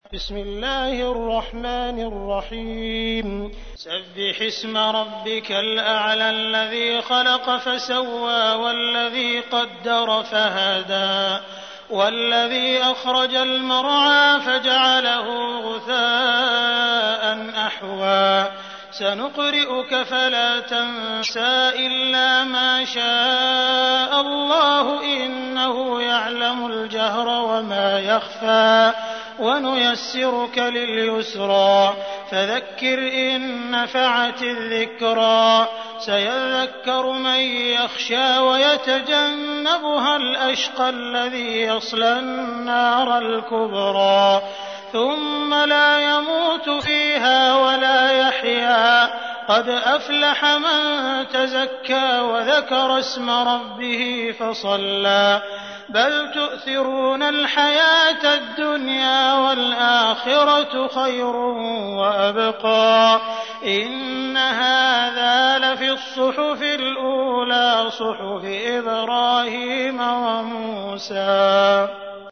تحميل : 87. سورة الأعلى / القارئ عبد الرحمن السديس / القرآن الكريم / موقع يا حسين